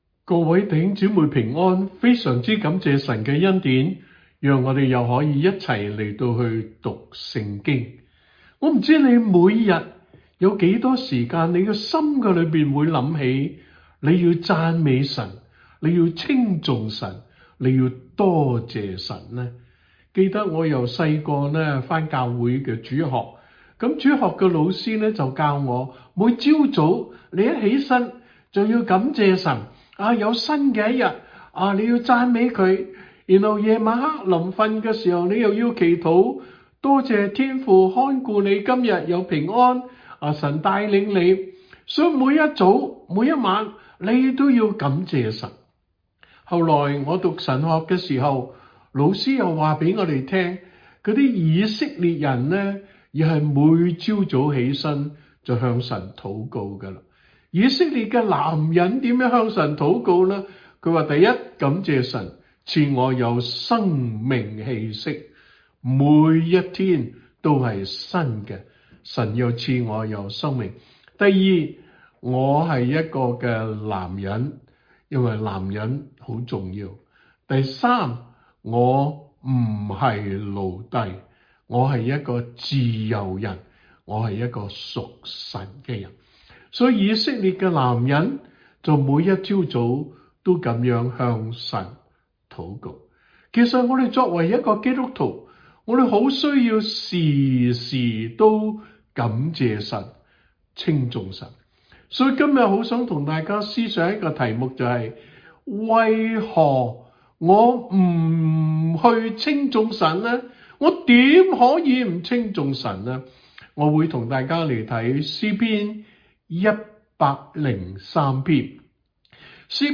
場所："陪你讀聖經"專題節目